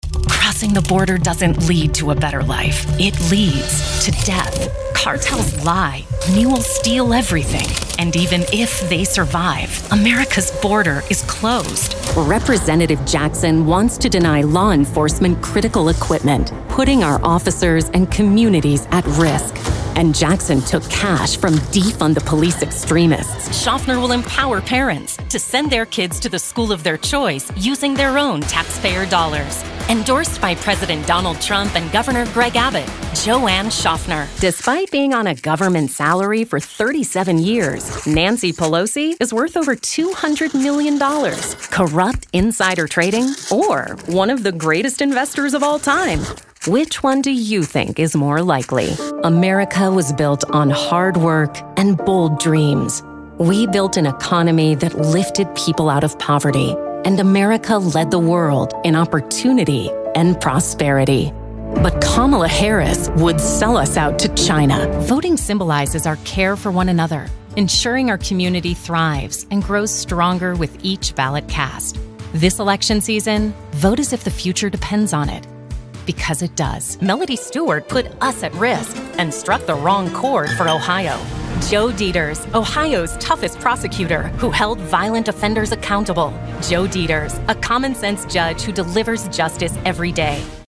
Chaleureux
Femelle
Annonces politiques
Sennheiser MK4
Mon studio bénéficie d’une isolation structurelle avec deux couches de placo 5/8", doubles portes extérieures avec sas, et un système de CVC indépendant du logement.